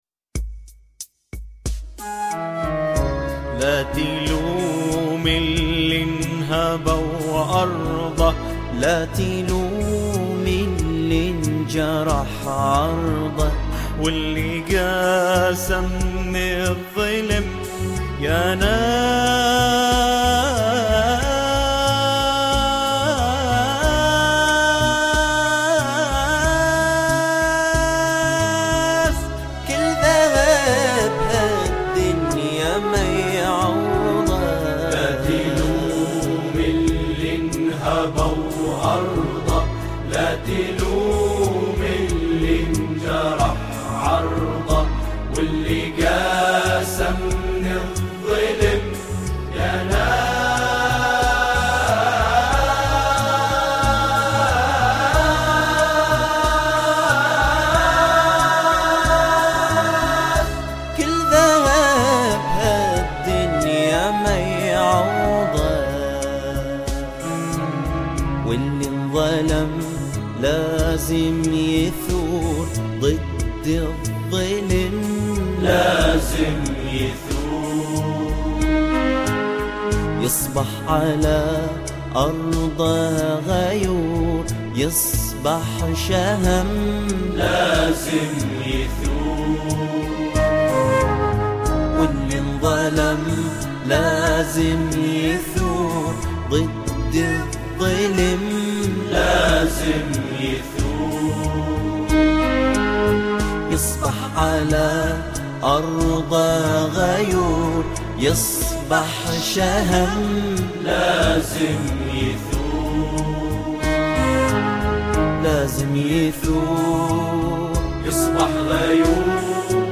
اشودة البحرين
أناشيد بحرينية